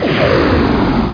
1 channel
monster.mp3